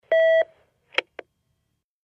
Intercom beep & in - line hang up